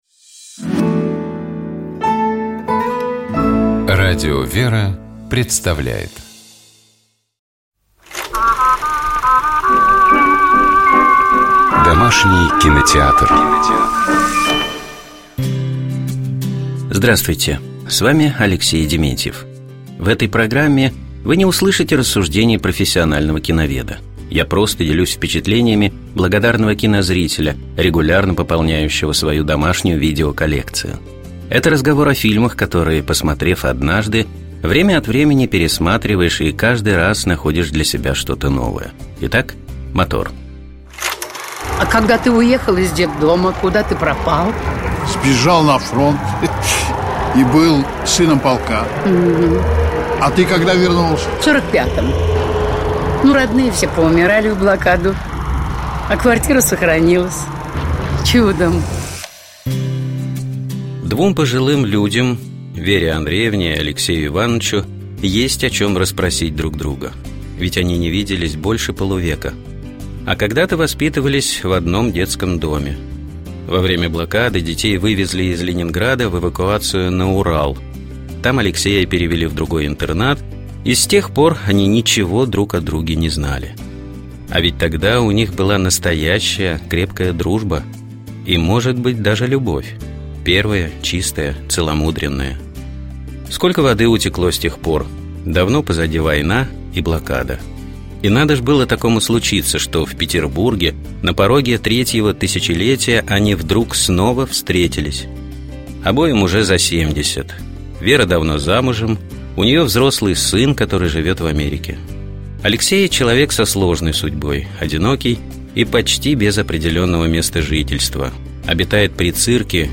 В начале нашей программы прозвучал фрагмент из этого кинофильма.